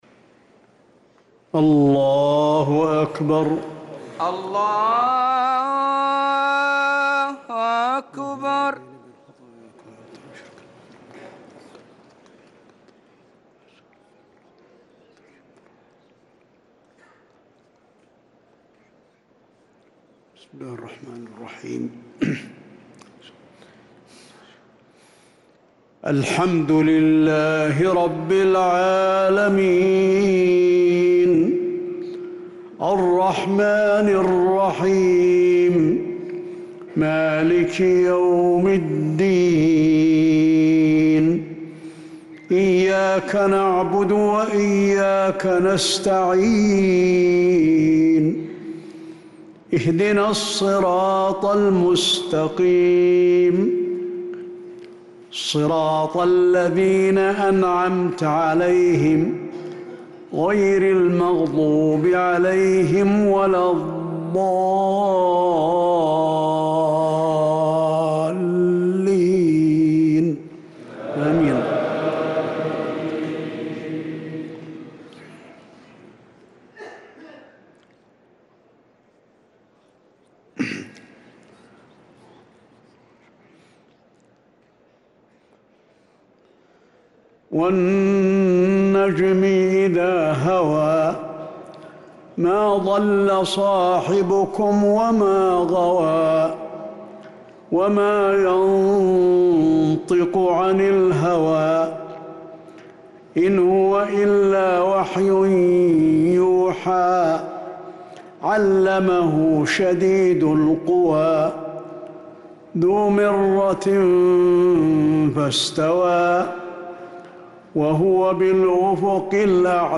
صلاة العشاء للقارئ علي الحذيفي 28 جمادي الأول 1446 هـ
تِلَاوَات الْحَرَمَيْن .